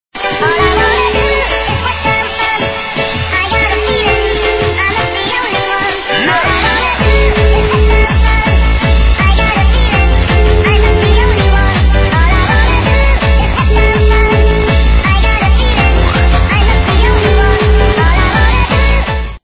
- западная эстрада